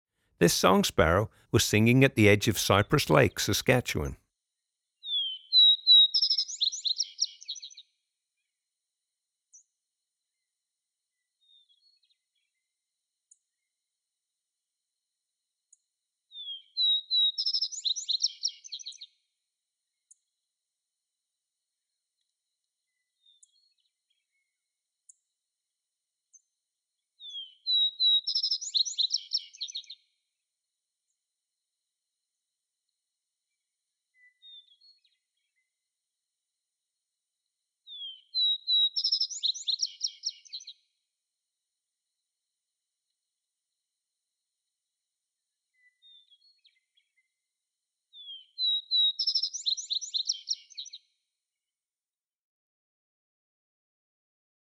Song Sparrow’s Song
85-song-sparrow.m4a